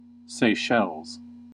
Seychelles[c] (/sˈʃɛl(z)/
En-us-Seychelles.ogg.mp3